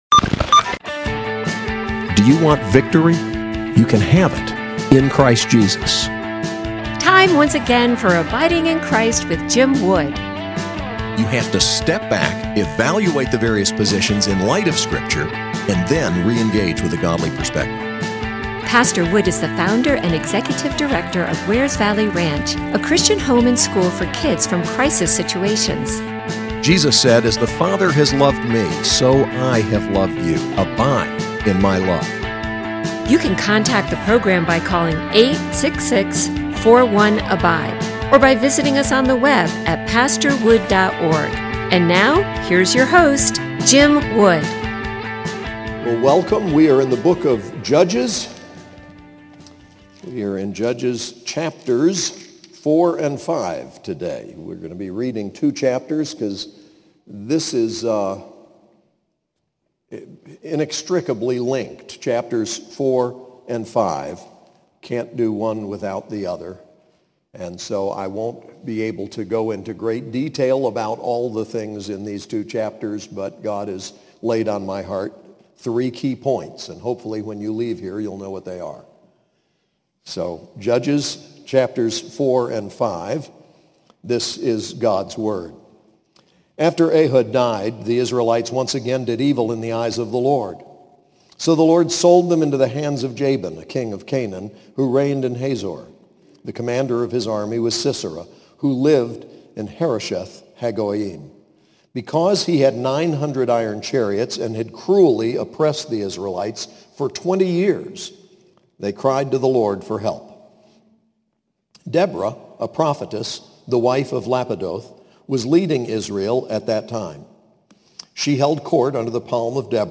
Covenant Community Church: Judges 4-5 Preacher